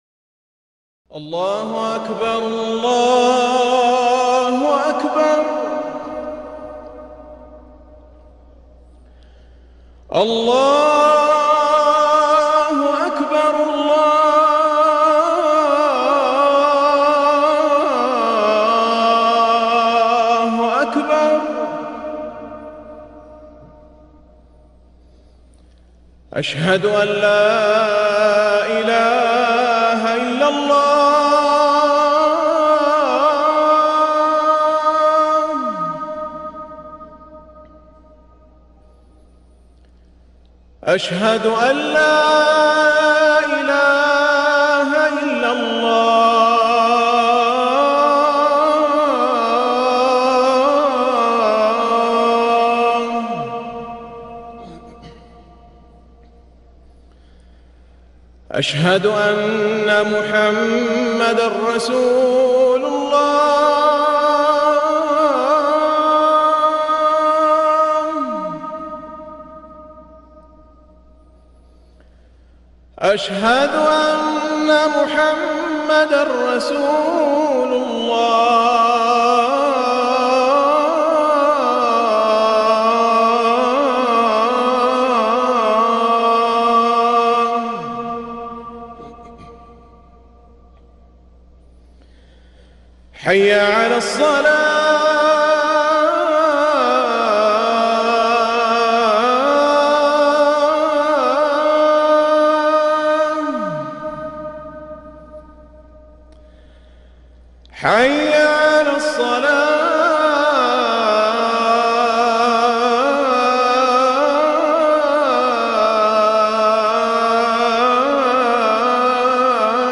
اذان المغرب